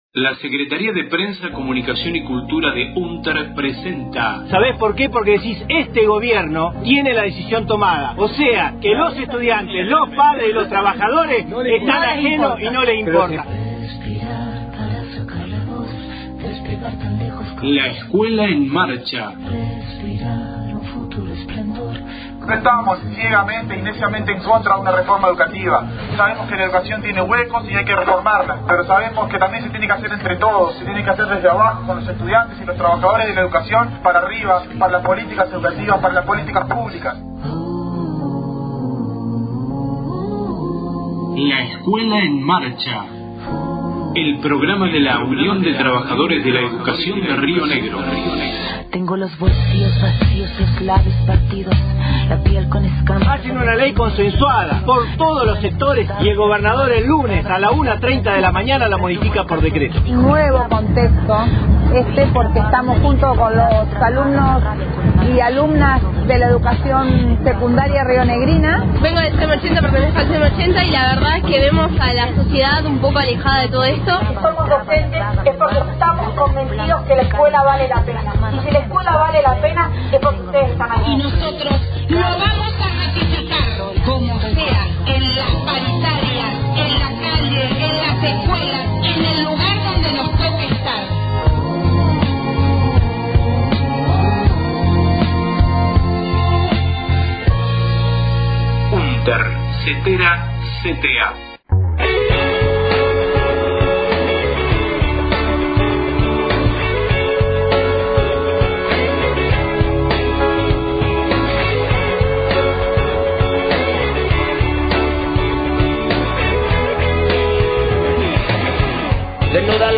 LEEM, radio 3/07/17.